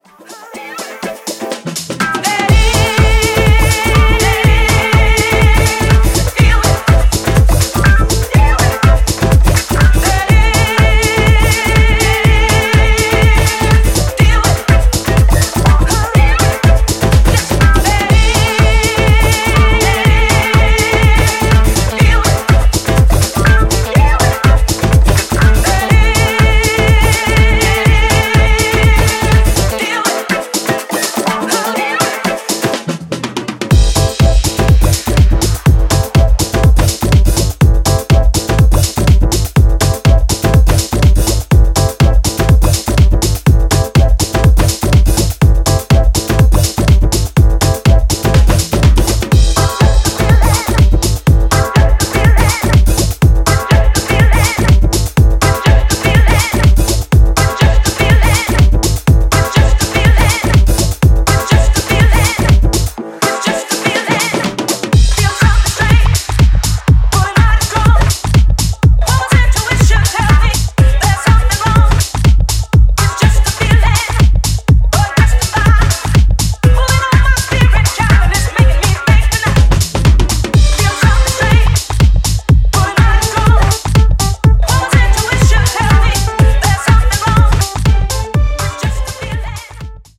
ファンキーでソウルフル！
ジャンル(スタイル) DISCO HOUSE / RE-EDIT